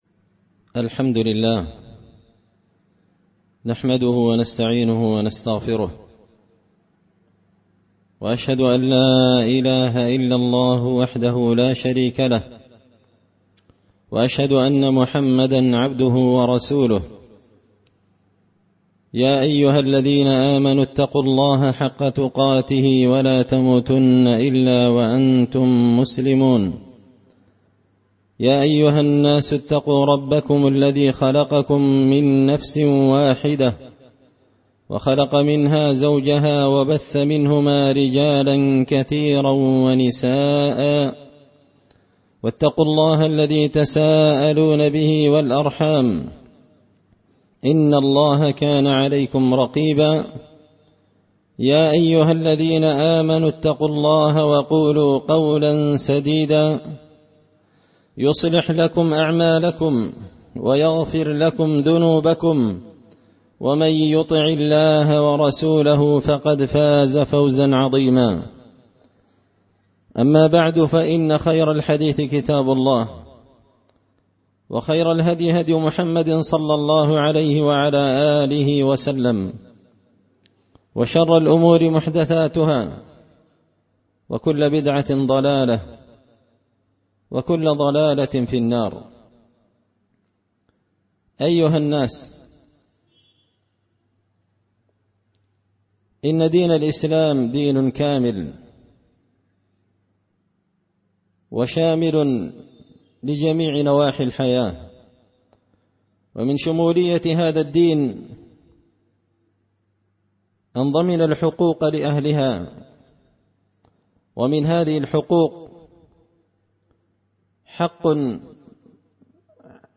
خطبة جمعة بعنوان – حق اليتيم
دار الحديث بمسجد الفرقان ـ قشن ـ المهرة ـ اليمن